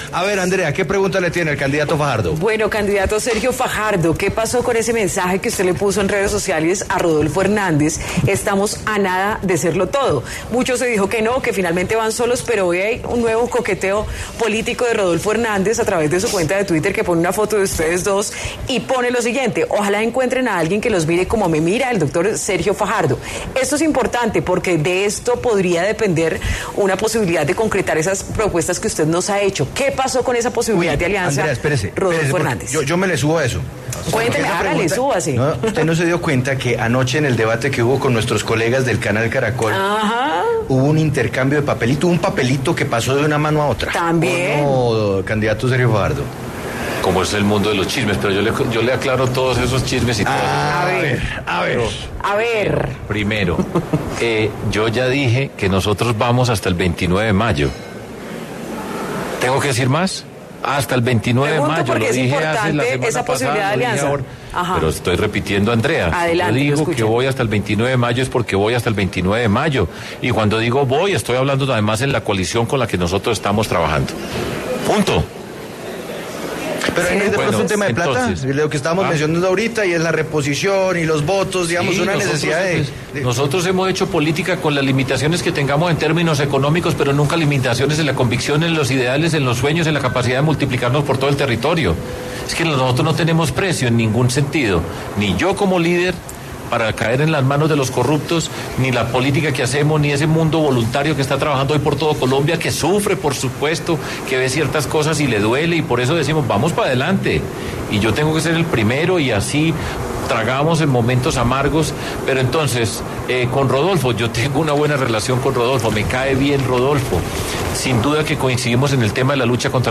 El candidato presidencial Sergio Fajardo respondió en Sigue La W sobre la posibilidad de aliarse con Rodolfo Hernández o el Pacto Histórico.